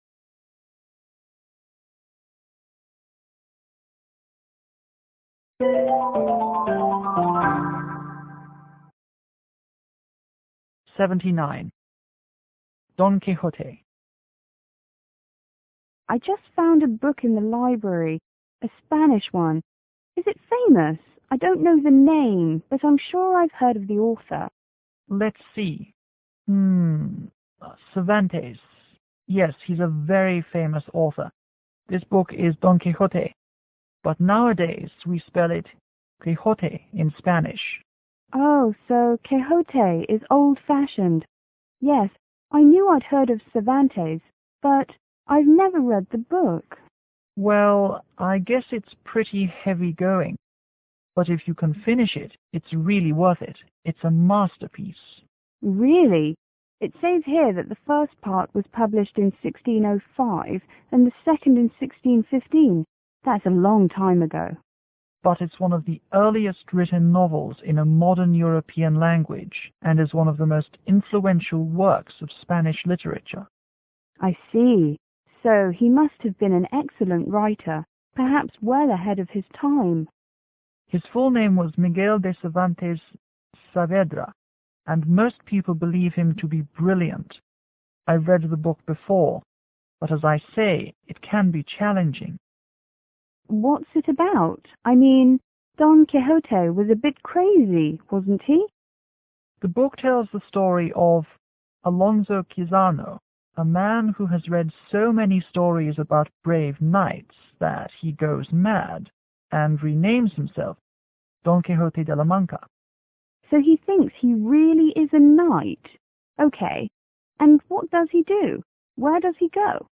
S1: Student    S2: Foreign student (From spain)